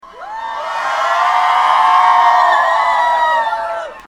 Crowd Ambience Concert 04
Crowd_ambience_concert_04.mp3